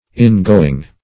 In-going \In"-go`ing\, n.